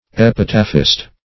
Epitaphist \Ep"i*taph`ist\, n.
epitaphist.mp3